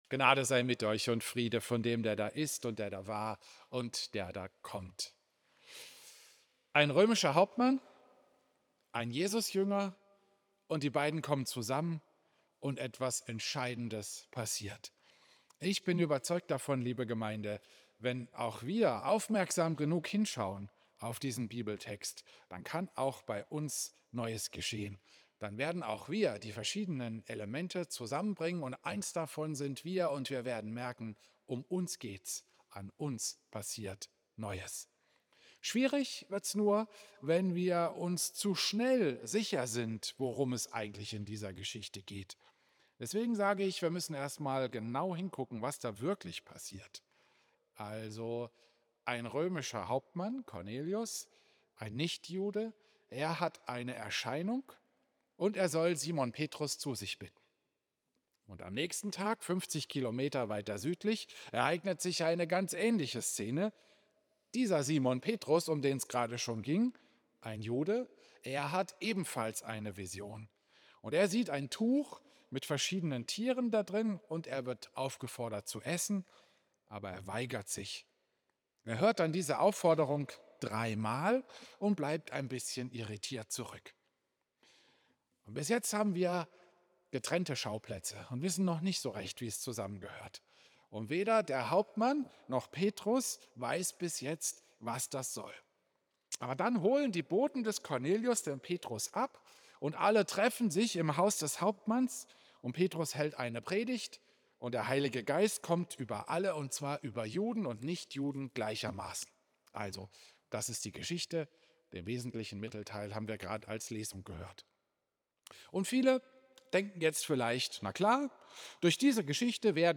Klosterkirche Volkenroda, 25. Januar 2026